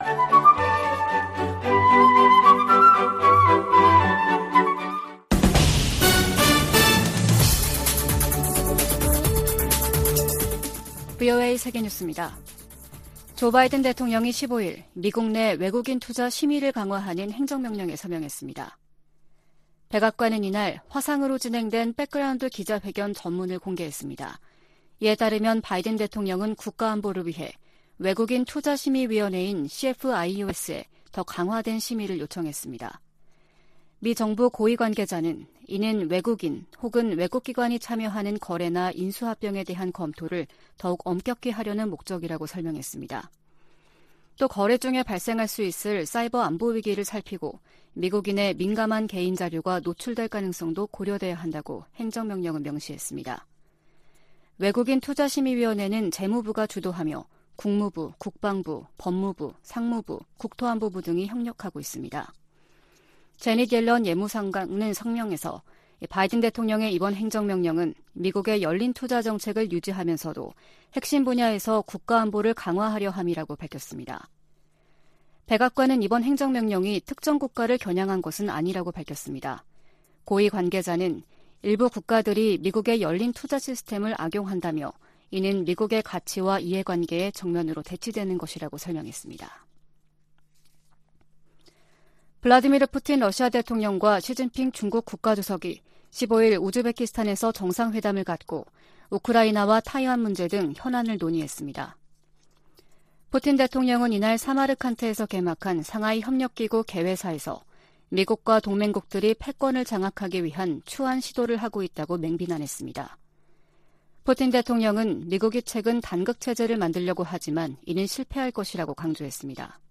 VOA 한국어 아침 뉴스 프로그램 '워싱턴 뉴스 광장' 2022년 9월 16일 방송입니다. 미 국방부는 북한의 핵무력 정책 법제화와 관련해 동맹의 안전 보장을 위해 모든 조치를 취할 것이라고 밝혔습니다. 미 국제기구대표부는 북한의 핵무력 법제화에 우려를 표명하며 북한은 결코 핵무기 보유국 지위를 얻을 수 없을 것이라고 강조했습니다. 미 상원의원들이 대북 압박과 억지를 유지하고 대북특별대표직을 신설할 것 등을 요구하는 법안을 발의했습니다.